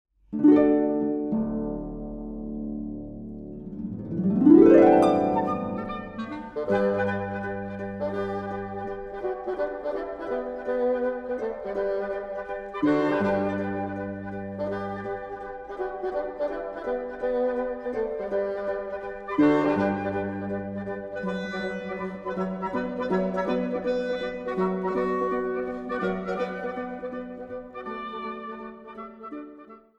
Flöte
Oboe und Englischhorn
Klarinette
Fagott
Harfe